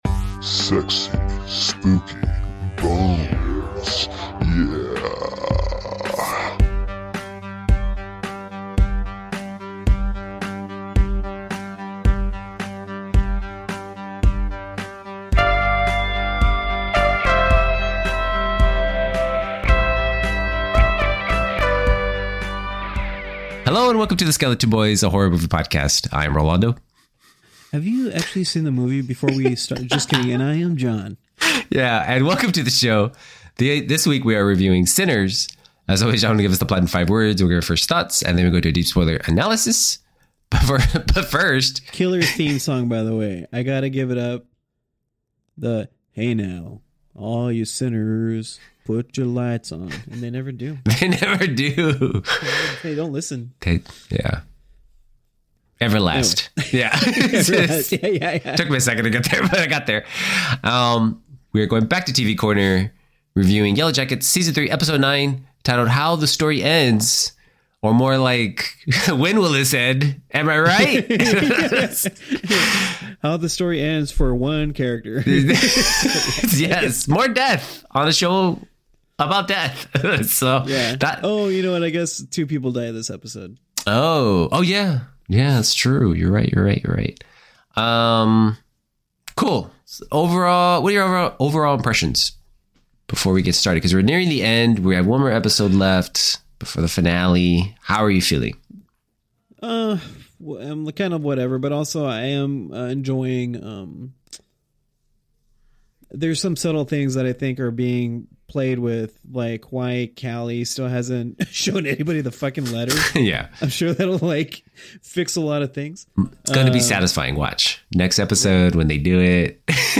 The Skeleton Boys is a horror movie podcast. Each week we review: new, vintage or disturbing horror movies. A humorous take on a serious genre.